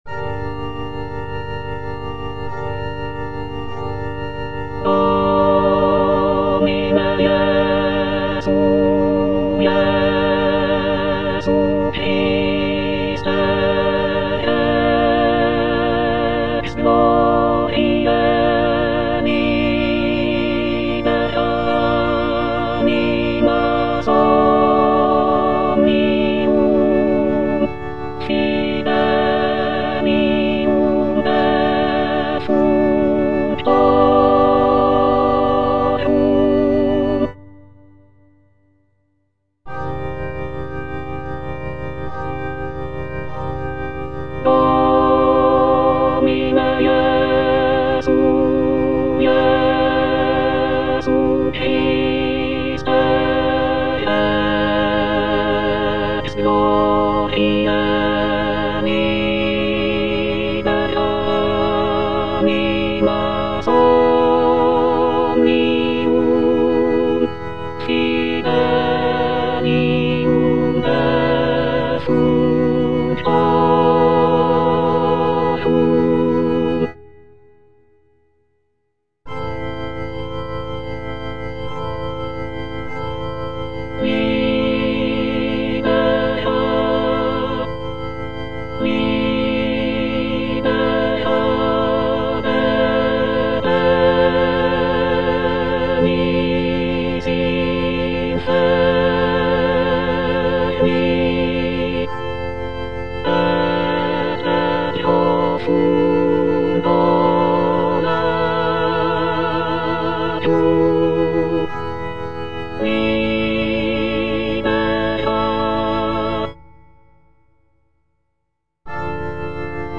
F. VON SUPPÈ - MISSA PRO DEFUNCTIS/REQUIEM Domine Jesu (All voices) Ads stop: auto-stop